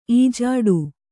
♪ ījāḍu